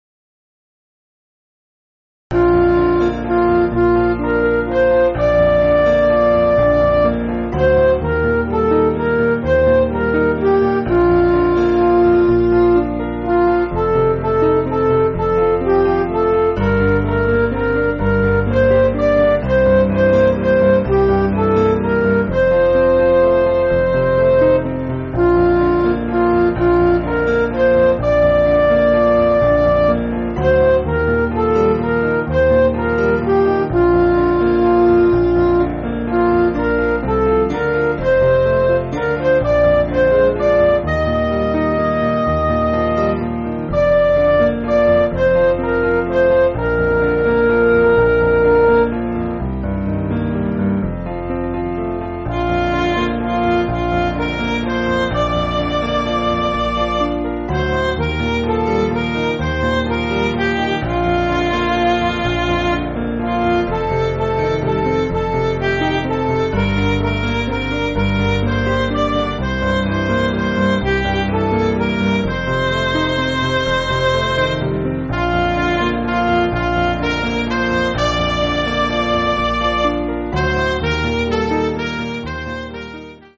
Piano & Instrumental
(CM)   2/Bb